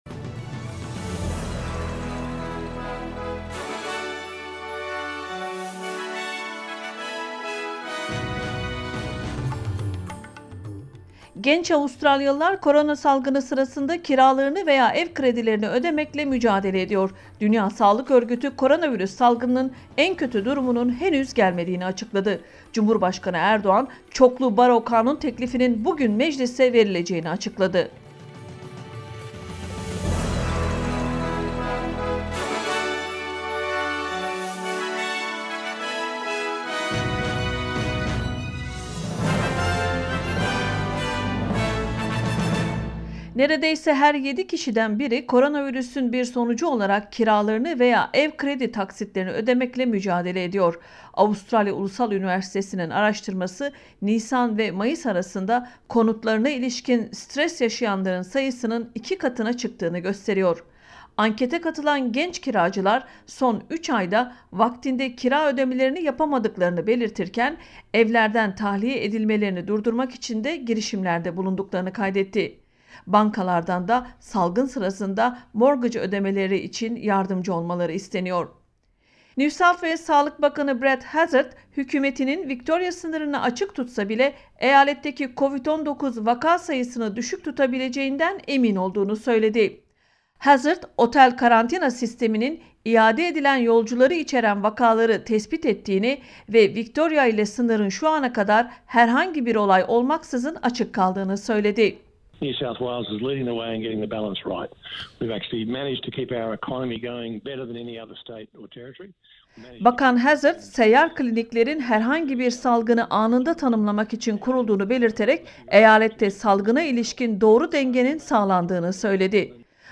news_bulletin.mp3